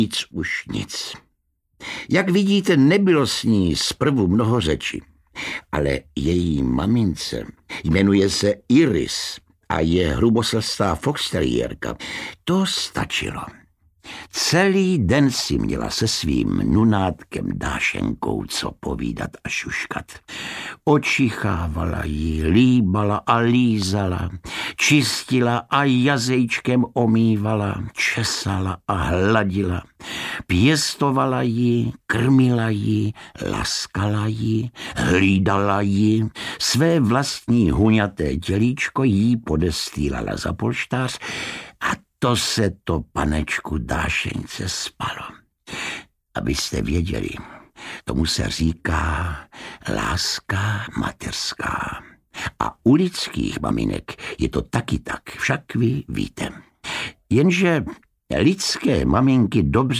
Audiobook
Read: Josef Somr